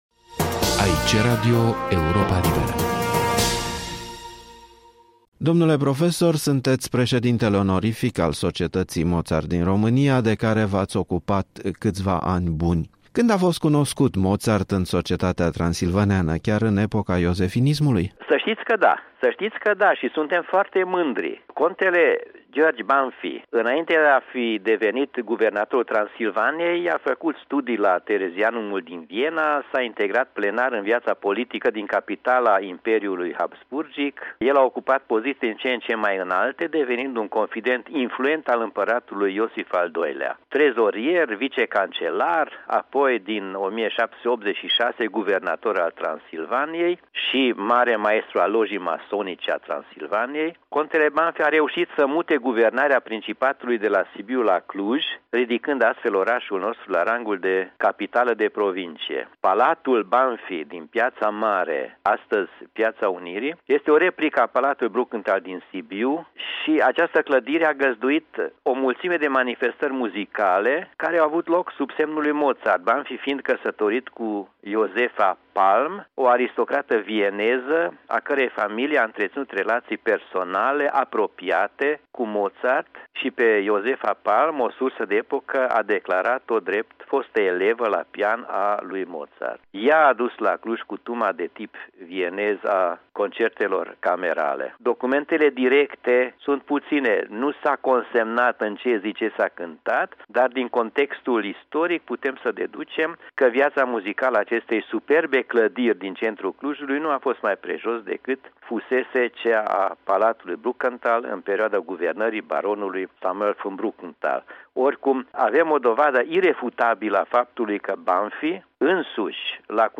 Arhiva sonoră a Europei Libere: aniversarea 250 de ani de la nașterea lui Mozart și lumea muzicală transilvăneană